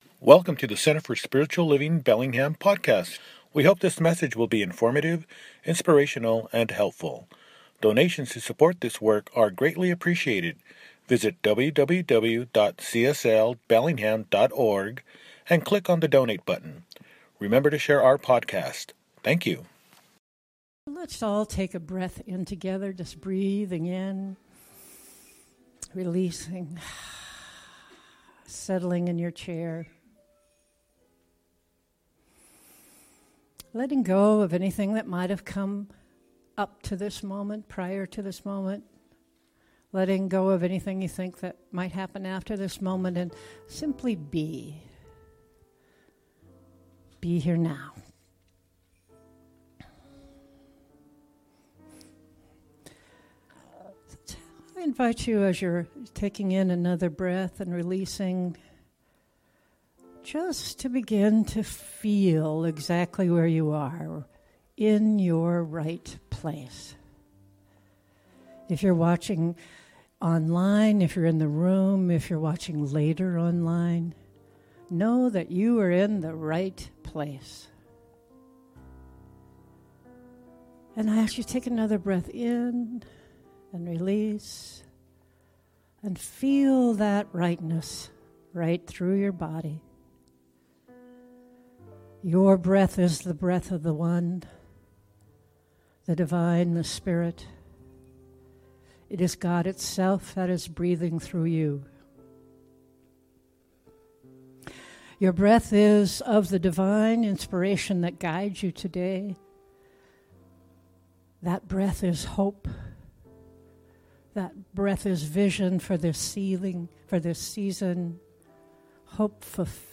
The Wonder Child – Celebration-Service